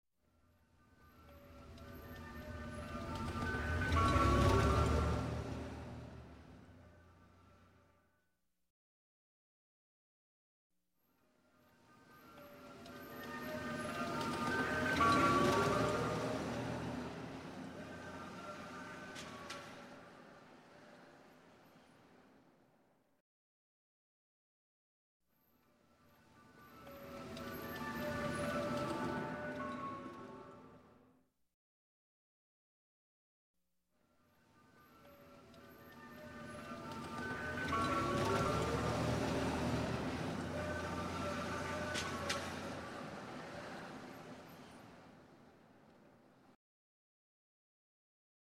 Грузовик с мороженым разъезжает по улицам и его веселая мелодия звучит издалека